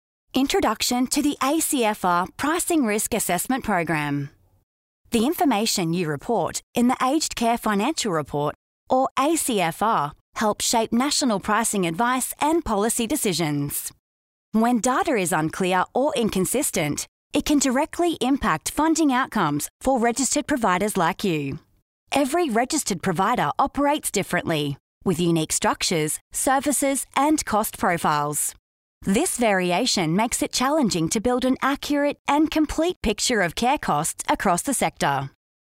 Female
Experienced & Versatile Australian Female Voice:
Described as Engaging, Upbeat, Fun, Fancy, Youth, Professional, Sensual, Caring, Motherly, Lively, Cool, Conversational, News Reader, MTV Host, On Hold, Sincere, Gov, Medical, Upbeat =)
Medical Explainer Video